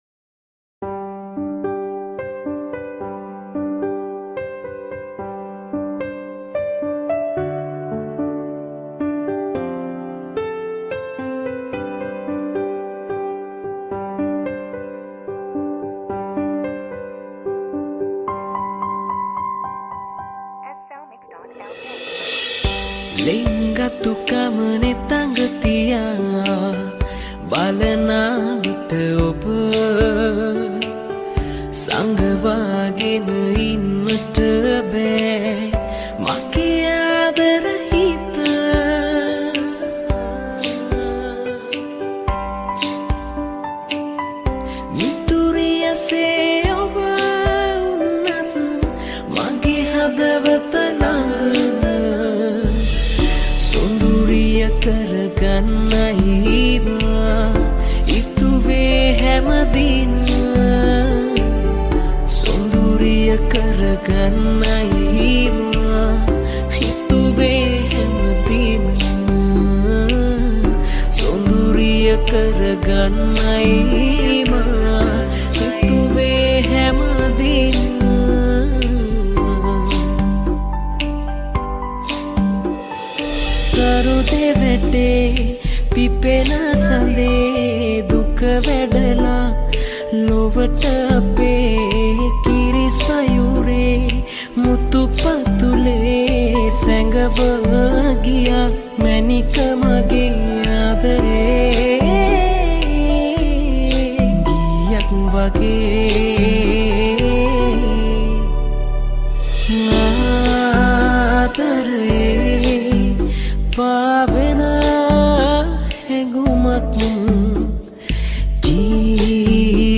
High quality Sri Lankan remix MP3 (4).
remix